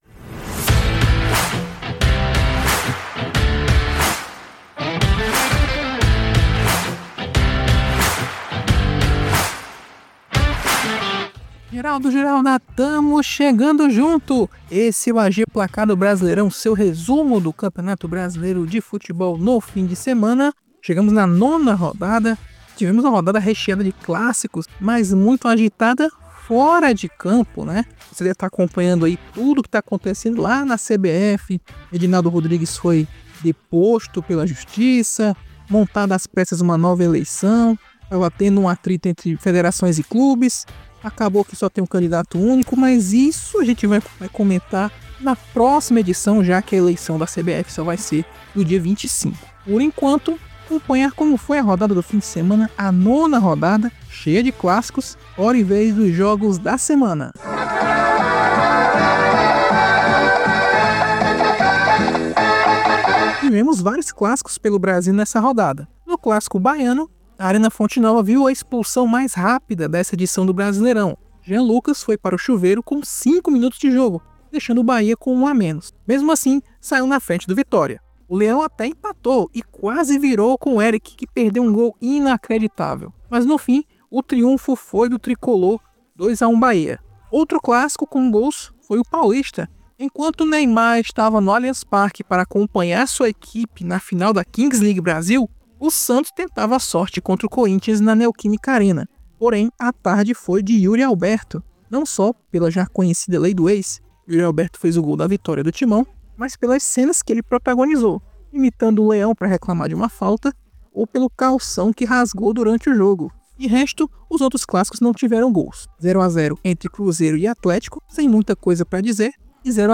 No Momento Voz da Rodada, ouça a comemoração dos jogadores do Palmeiras, o líder isolado do Brasileirão, no microfone da 102 FM de Bragança PAULISTA!